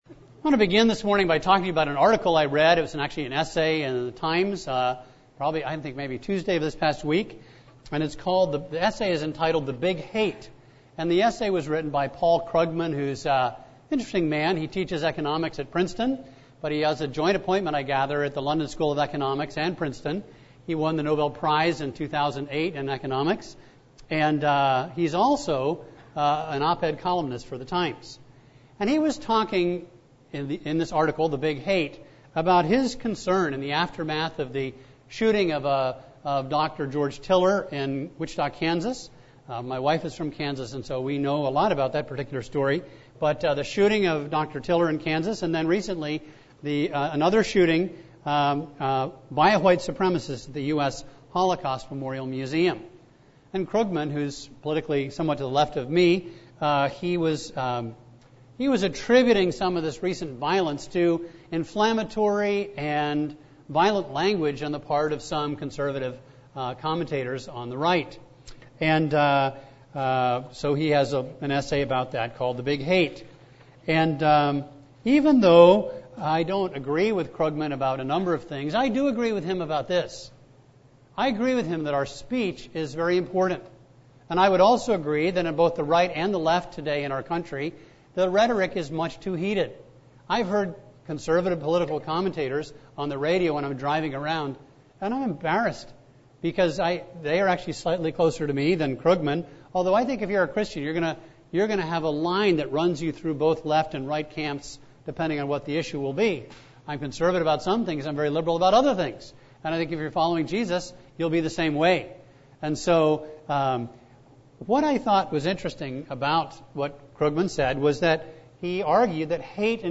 A message from the series "Ruth."